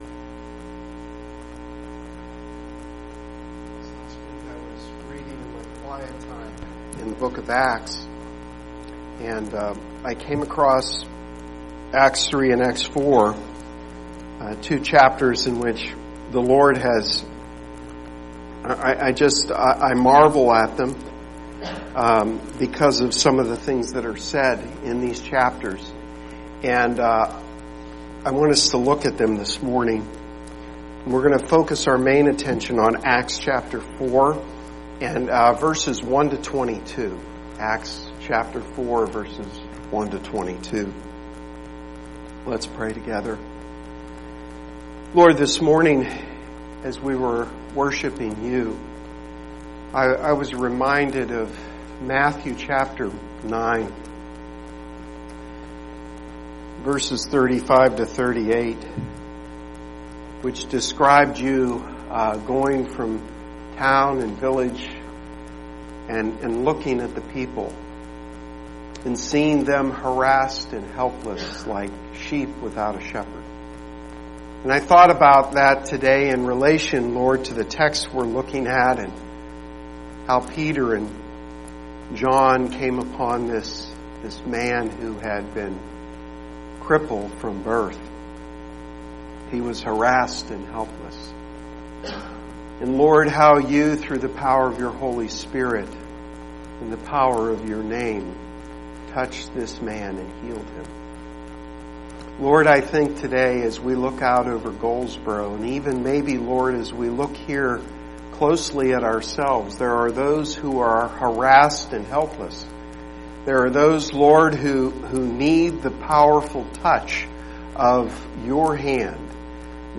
Sermon-6-10-18.mp3